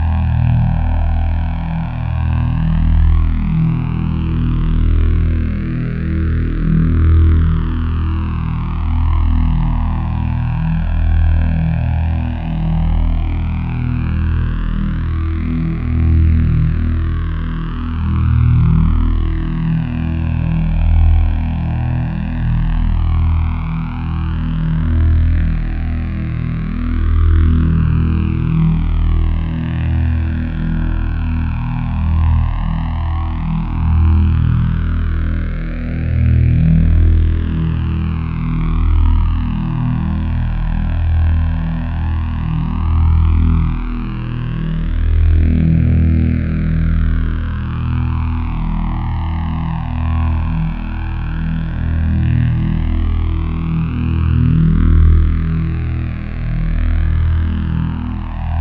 saber_hum.wav